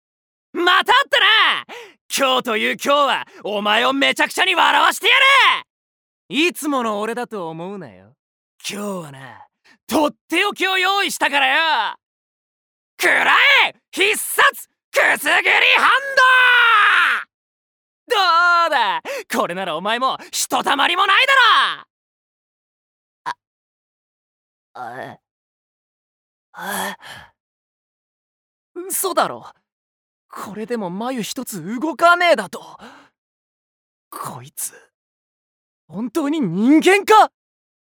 VOICE SAMPLE
セリフ1